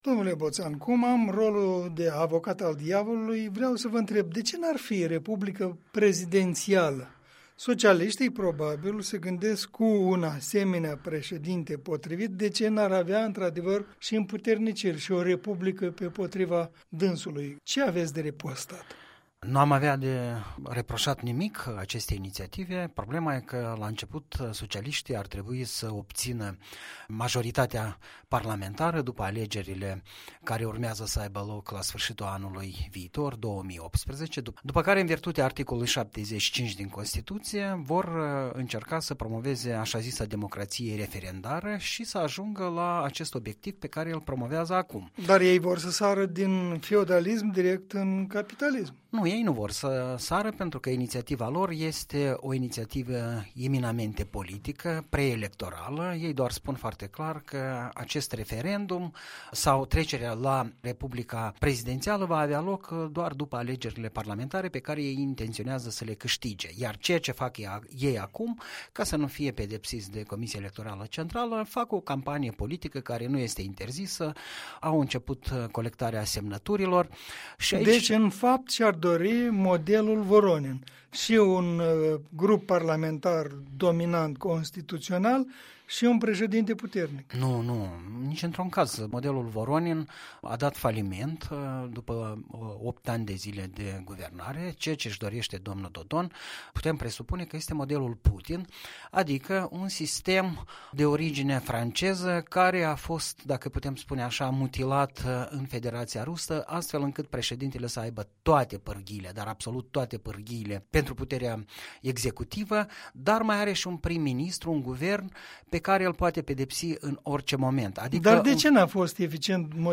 Punct de vedere săptămânal în dialog.